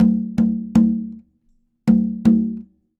Conga Fill 01.wav